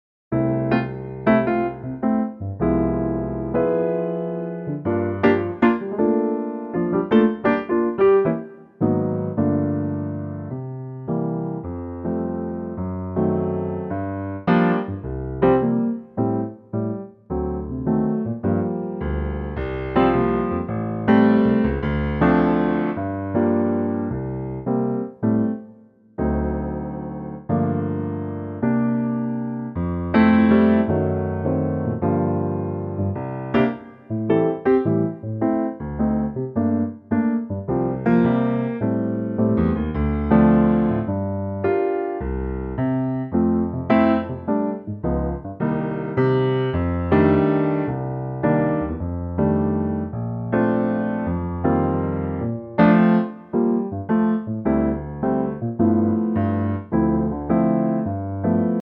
Unique Backing Tracks
key - F to Gb - vocal range - A to Db